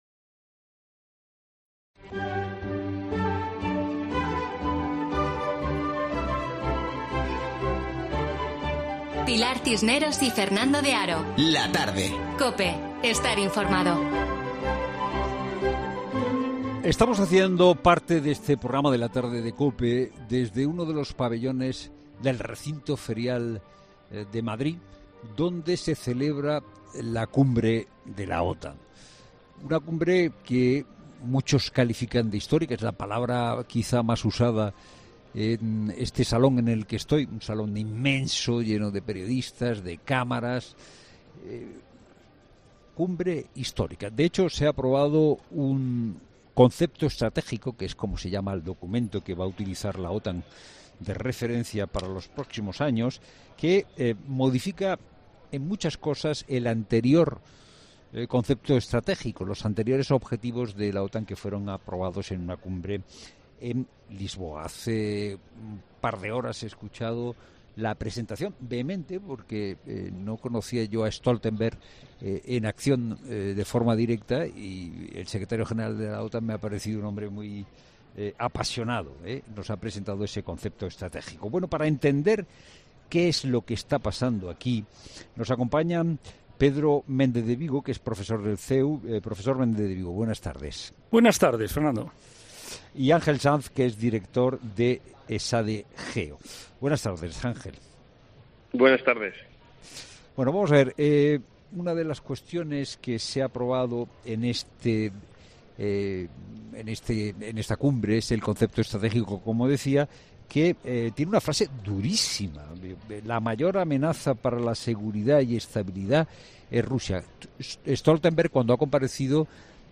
Estamos haciendo parte de La Tarde desde IFEMA donde se celebra la Cumbre de la OTAN.